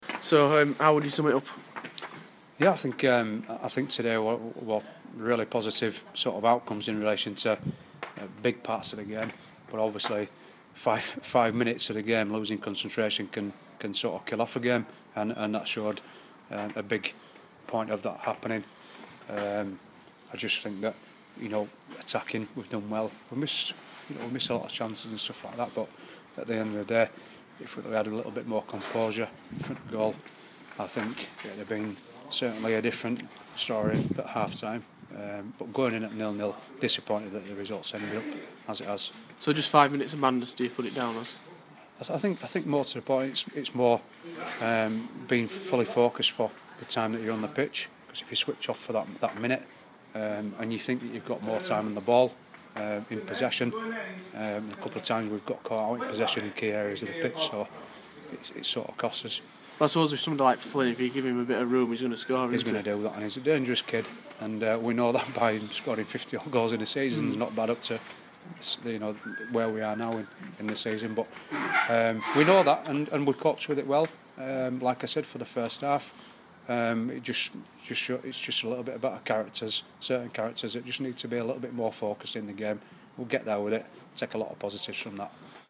speaking after Eccleshill United's 4-1 defeat to AFC Emley.